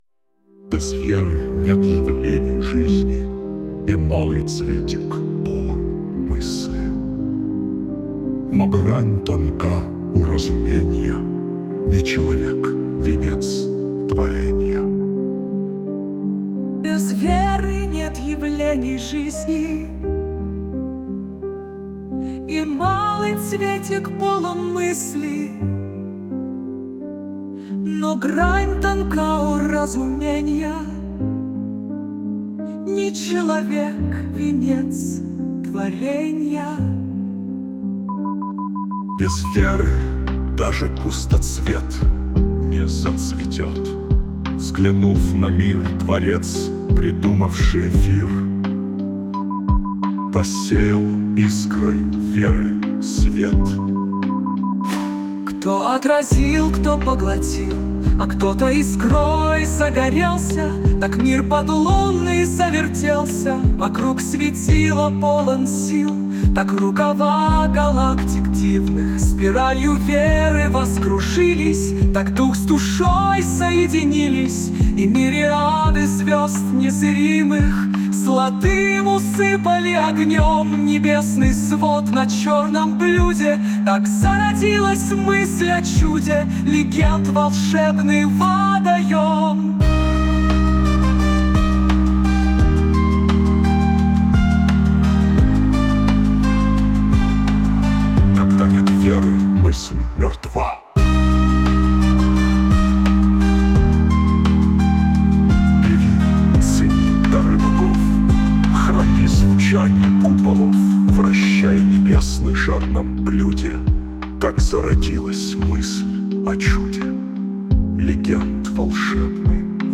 Скачать с сервера (3.55 Mb) ] в стиле Мистерии Suno